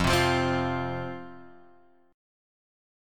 F5 chord {1 3 3 x 1 1} chord